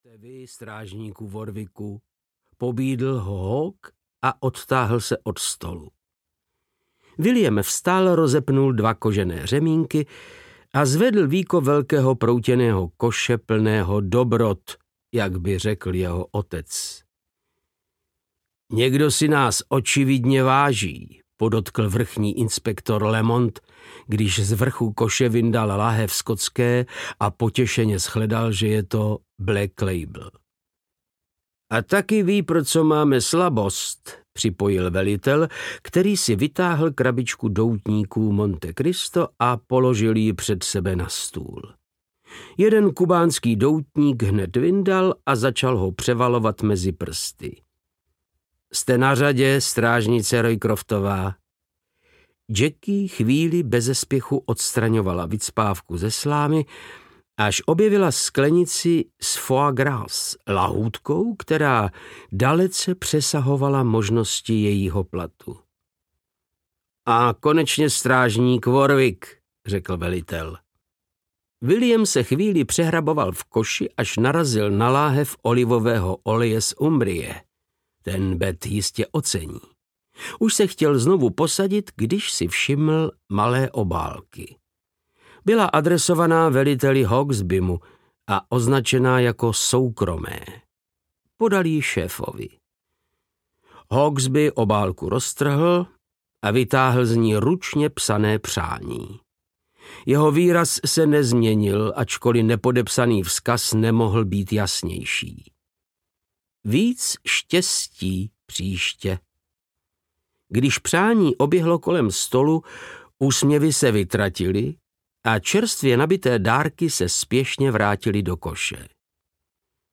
Přímo před nosem audiokniha
Ukázka z knihy
• InterpretVáclav Knop